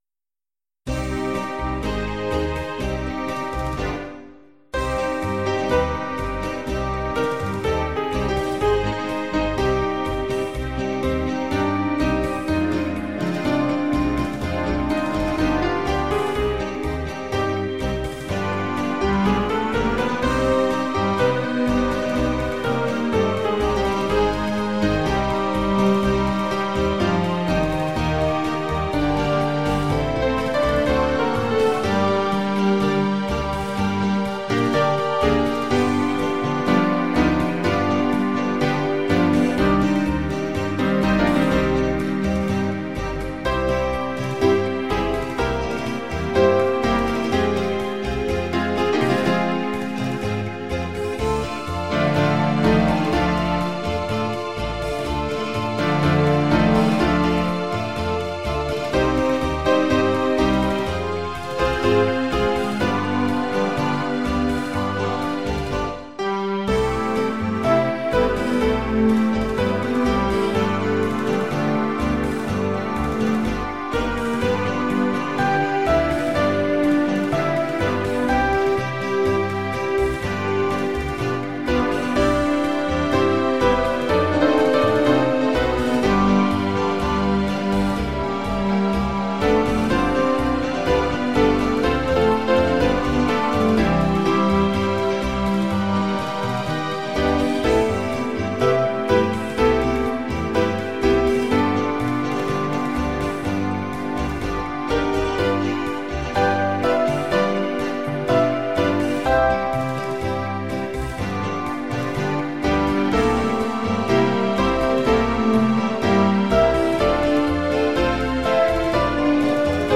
8-beat intro.
Verse is in the key of Cm, chorus is in C.